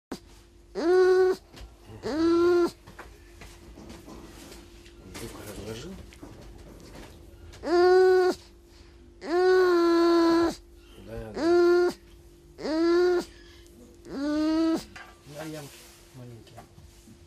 Nerpa.mp3